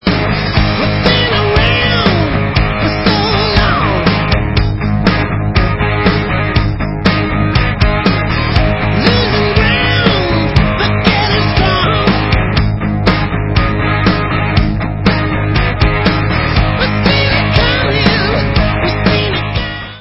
rocková kapela
studiové album